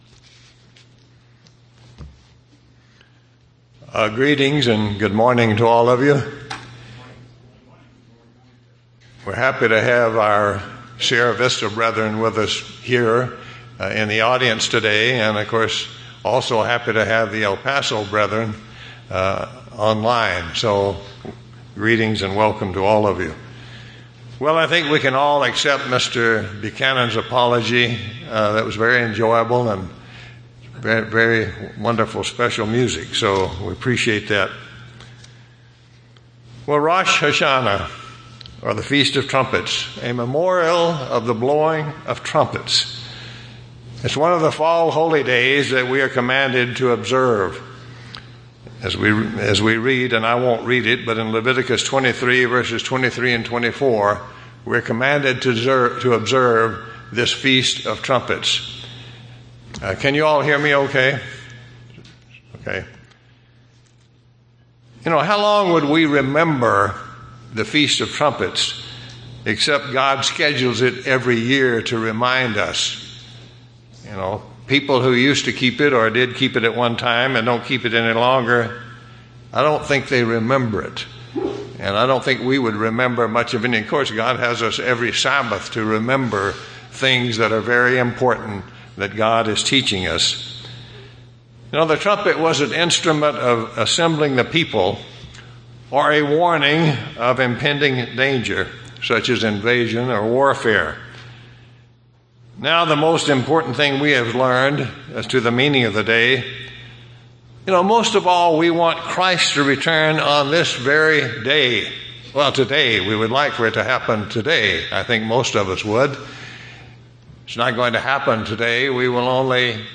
Given in Tucson, AZ
UCG Sermon Studying the bible?